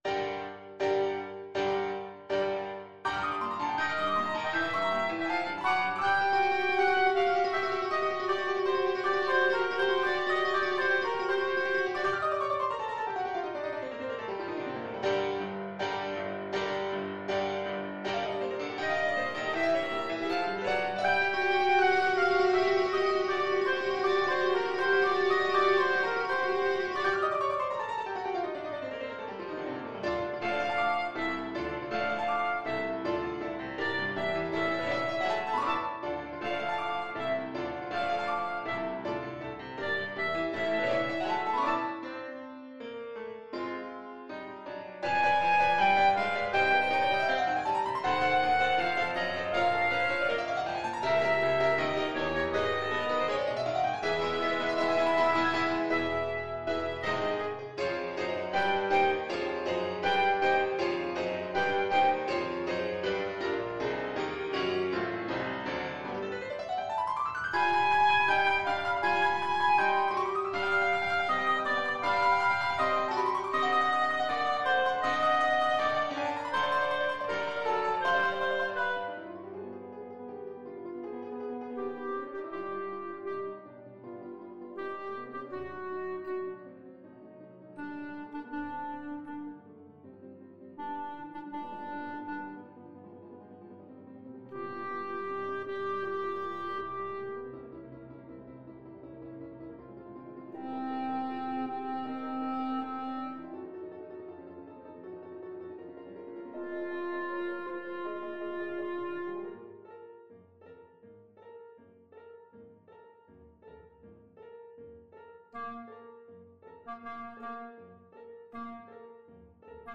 Clarinet
4/4 (View more 4/4 Music)
Allegro agitato (=80) (View more music marked Allegro)
G minor (Sounding Pitch) A minor (Clarinet in Bb) (View more G minor Music for Clarinet )
Classical (View more Classical Clarinet Music)